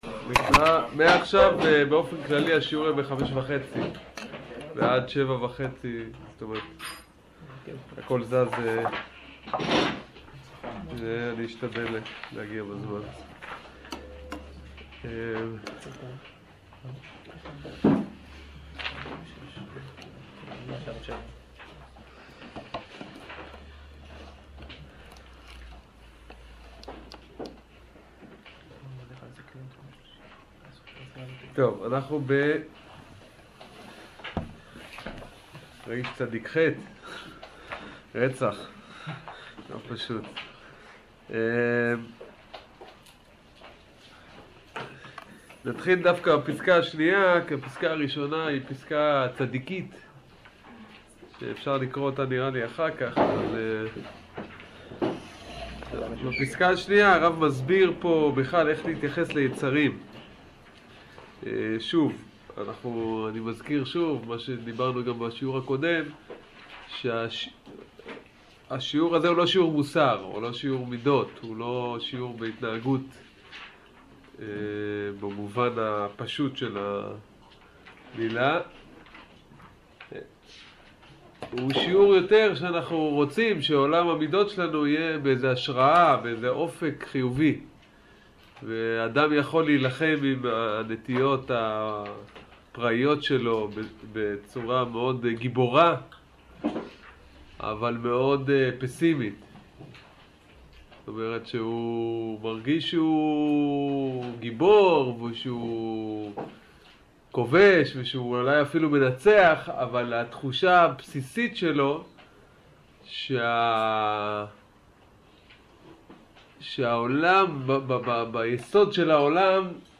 שיעור עמ' רח"צ